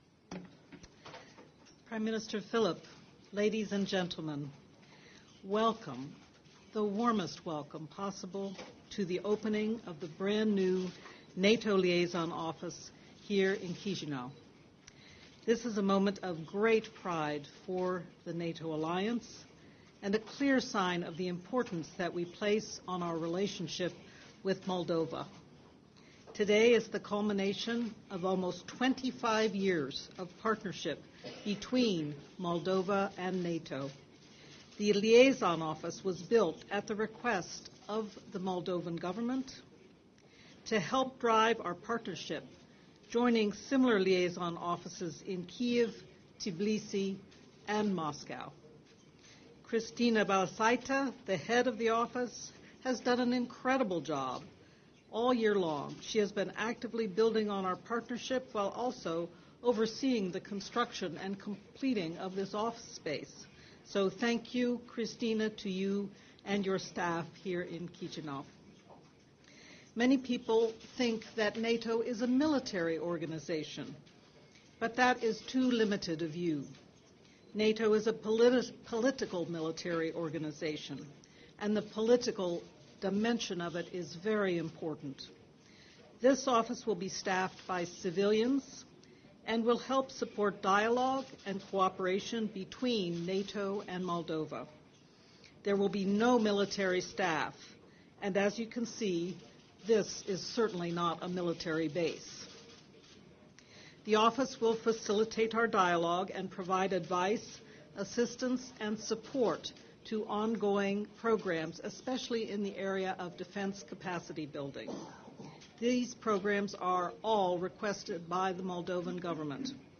Joint press point between NATO Deputy Secretary General Rose Gottemoeller and the Prime Minister of Moldova, Pavel Filip